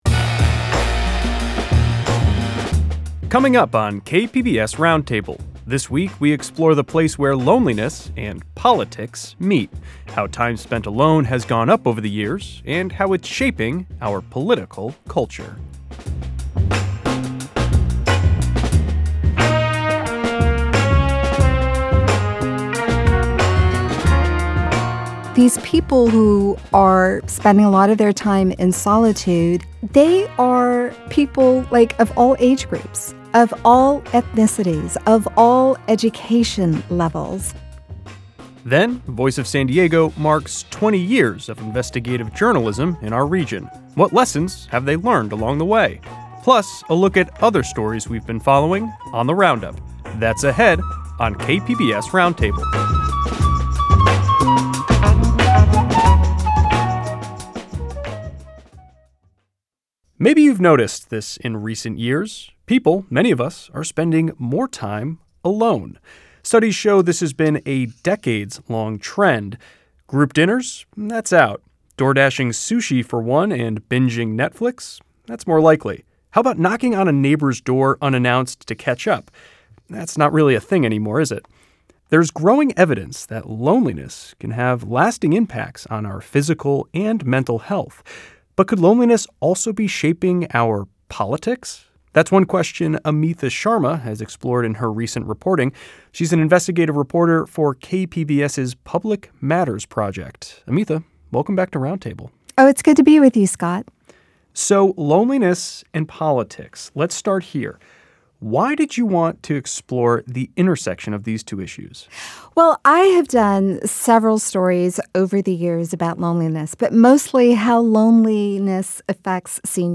KPBS Roundtable offers perspectives from those who tell San Diego’s stories. Join us for a weekly discussion with reporters, adding depth and context to the headlines driving the news in the San Diego region.